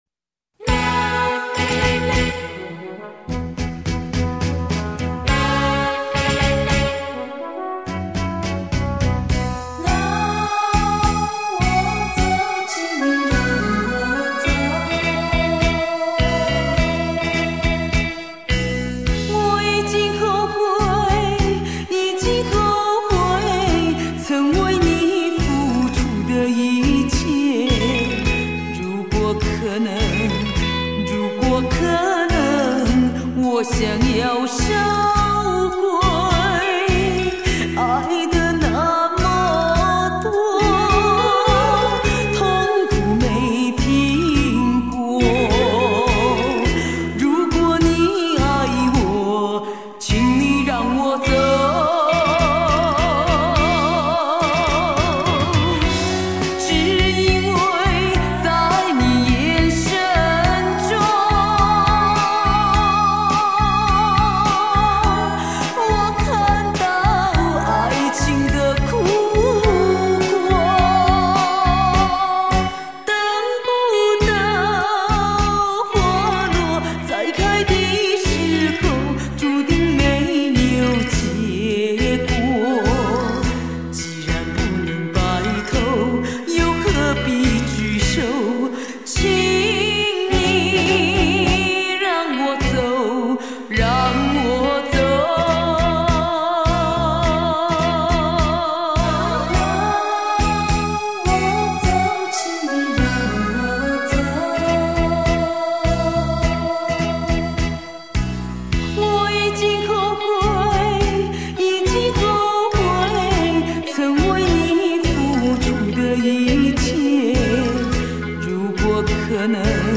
舞厅规格歌唱版
舞厅规格
探戈歌唱版